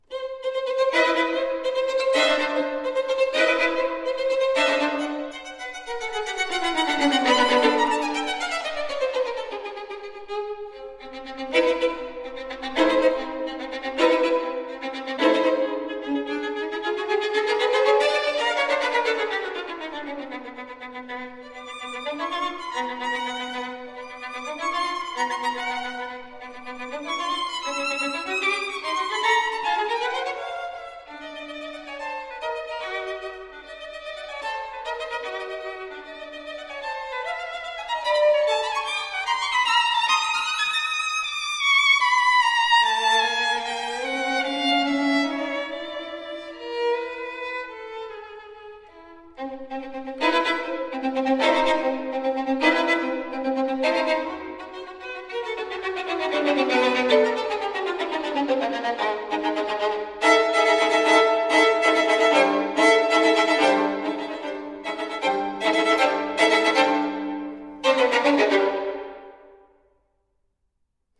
Violine
Dynamik und Chromatik (Tarantelle)